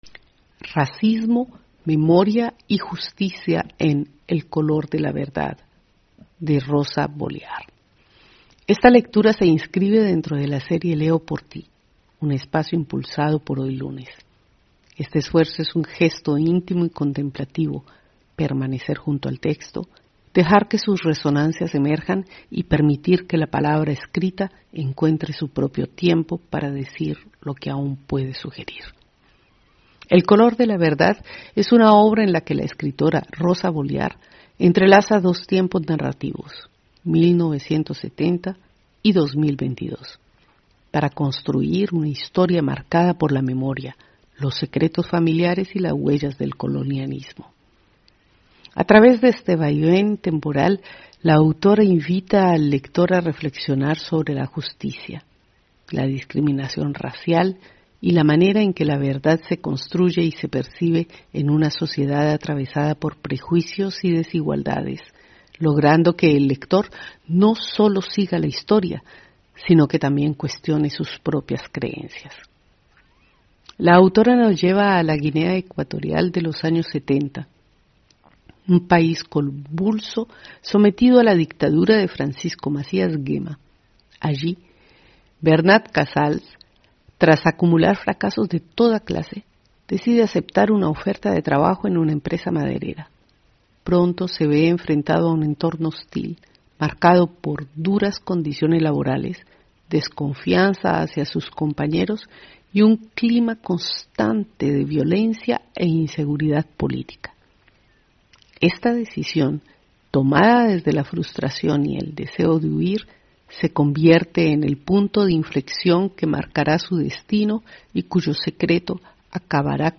HoyLunes – Esta lectura se inscribe dentro de la serie «Leo por ti», un espacio impulsado por ‘HoyLunes’.